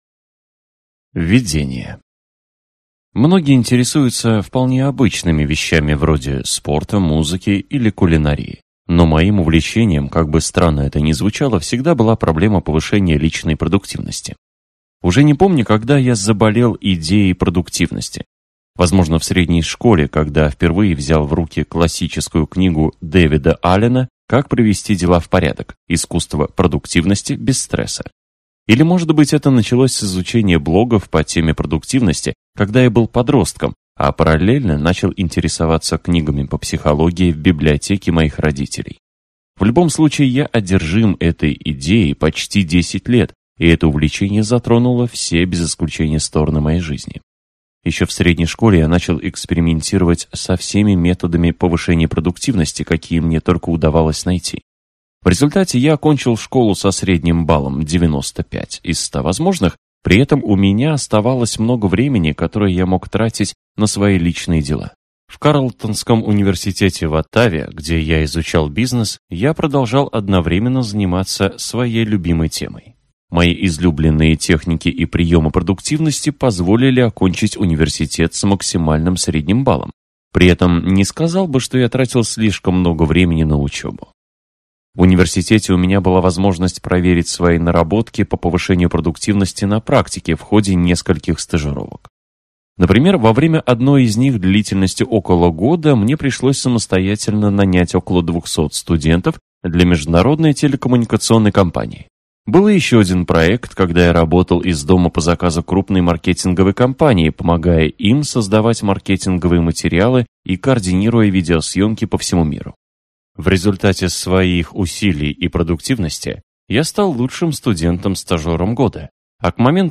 Аудиокнига Мой продуктивный год: Как я проверил самые известные методики личной эффективности на себе - купить, скачать и слушать онлайн | КнигоПоиск